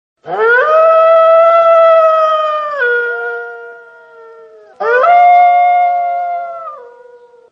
Звук - Койот
Отличного качества, без посторонних шумов.